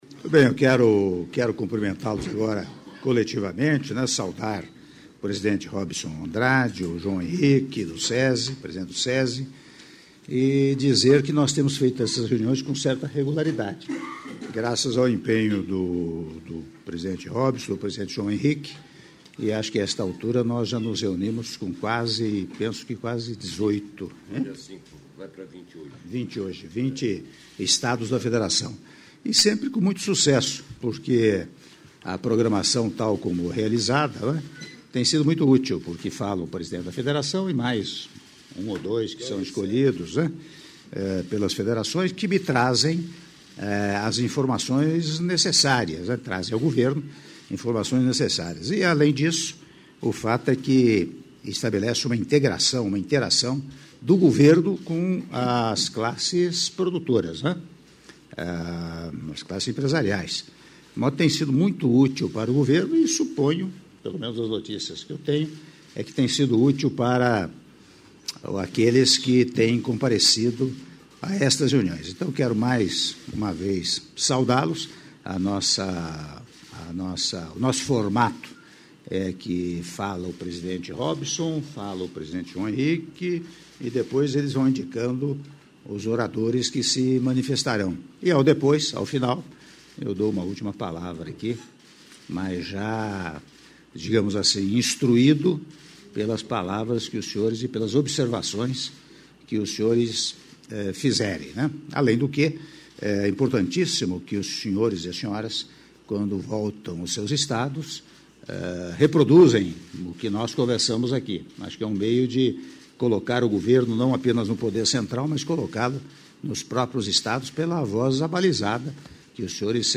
Áudio da abertura do presidente da República, Michel Temer, durante encontro com o Conselho Nacional do SESI - Palácio do Planalto/DF (02min13s) — Biblioteca